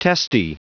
Prononciation du mot testy en anglais (fichier audio)
Prononciation du mot : testy